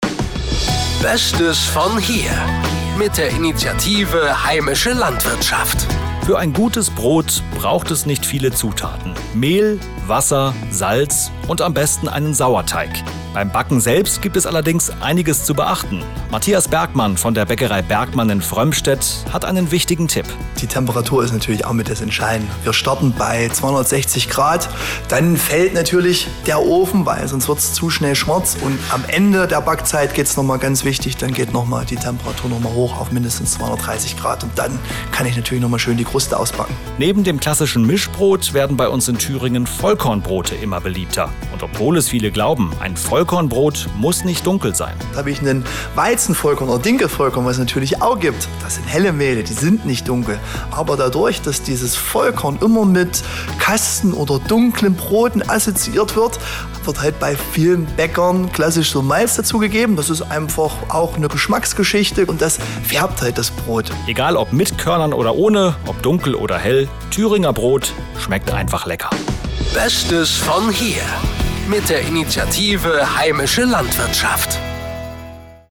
Darin kamen Landwirte, Müller und Bäcker zu Wort und zeigen, welchen Anteil sie daran haben, dass heimisches Brot entsteht.
lw_brotzeit_infomercial_6_baecker.mp3